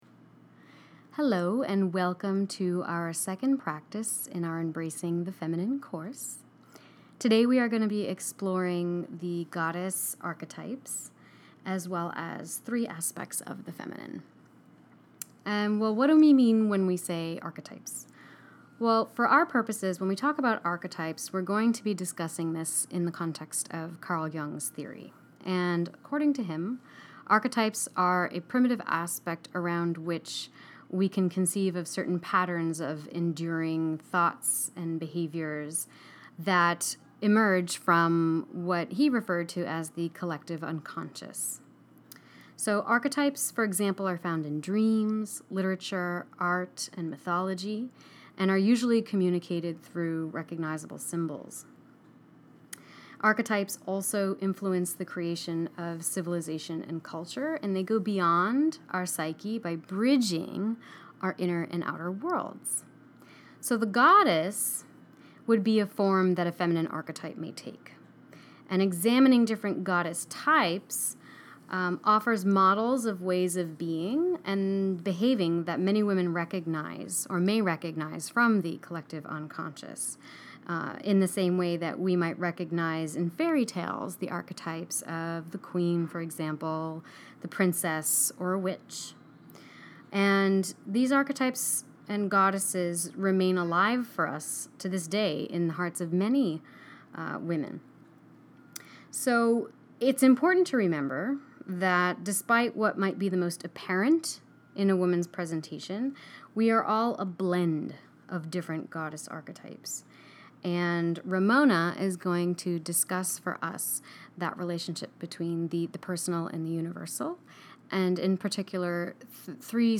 Audio Lecture and Discussion_Three Feminine Aspects